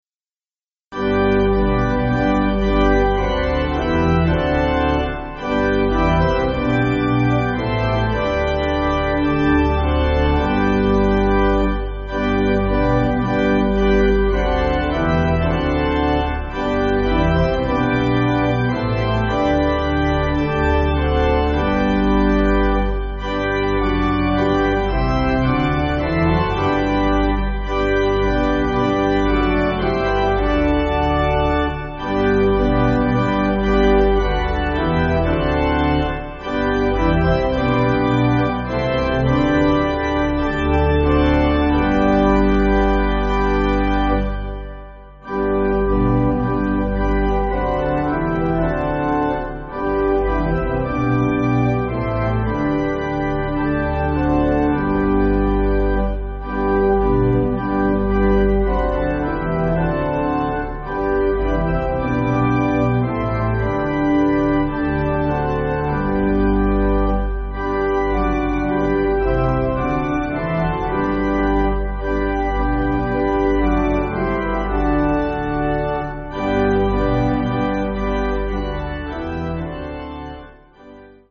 (CM)   5/G